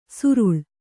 ♪ suruḷ